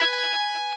guitar_009.ogg